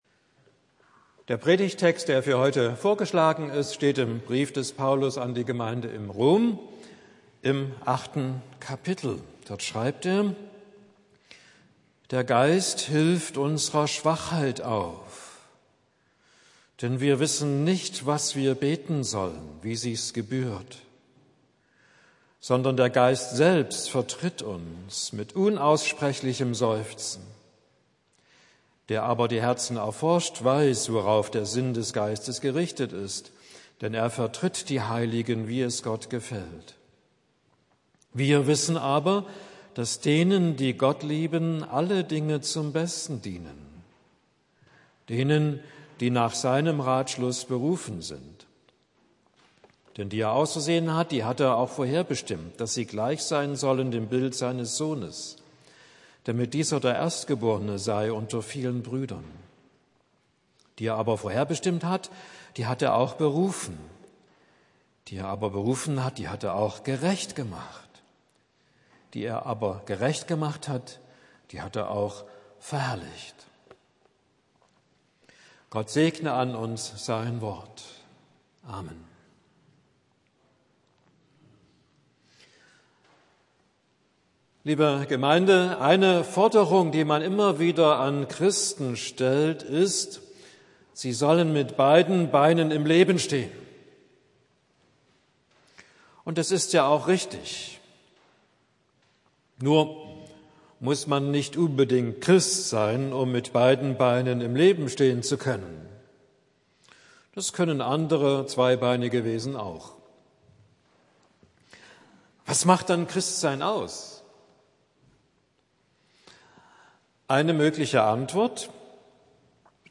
Predigt für den Sonntag Exaudi